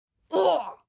man_injured.wav